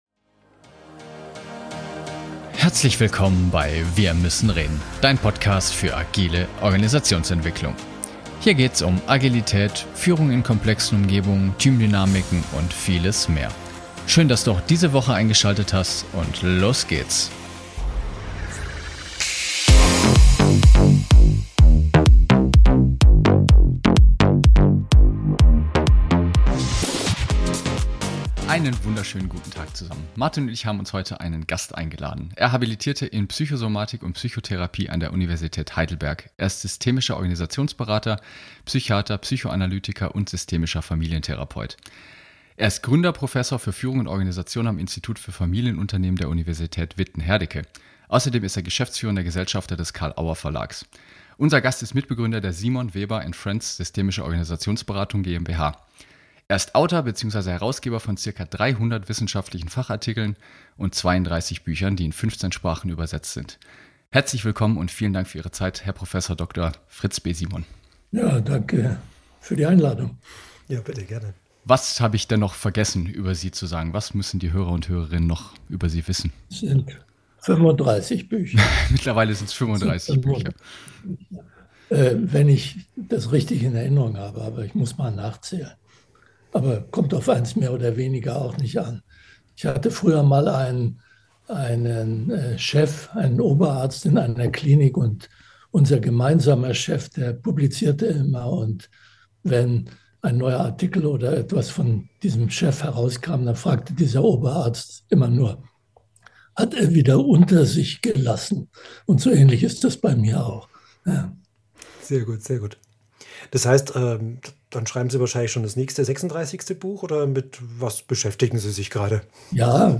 Beschreibung vor 1 Jahr Wir freuen uns sehr euch unser Gespräch mit Prof. Dr. Fritz B. Simon zu teilen.